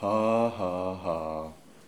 hahaha-lassitude_01.wav